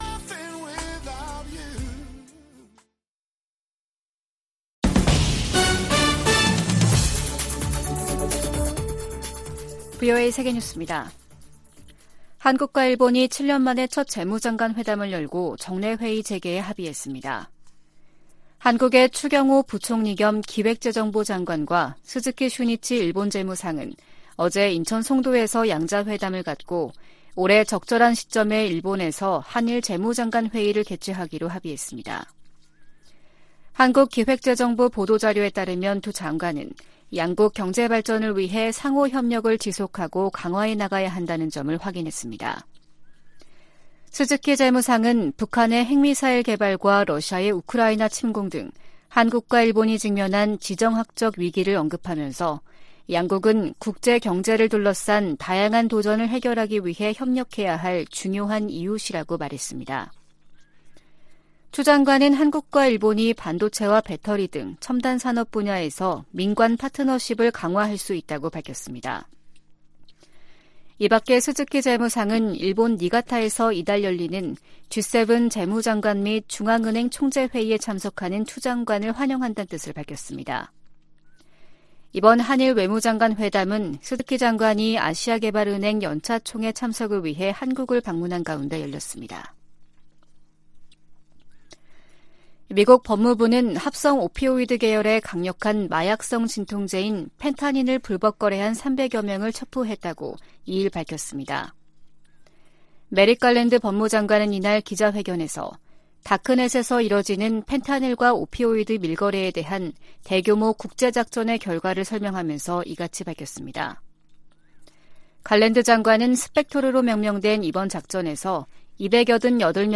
VOA 한국어 아침 뉴스 프로그램 '워싱턴 뉴스 광장' 2023년 5월 3일 방송입니다. 한일·미한일 정상회담이 이달 중 연이어 개최 될 예정입니다. 미 국무부는 워싱턴 선언은 북한의 핵 위협에 대한 미국의 억제력 강화 조치라며, 북한의 최근 비난을 일축했습니다. 미 국제종교자유위원회가 북한을 종교자유 특별우려국으로 재지정할 것을 권고했습니다.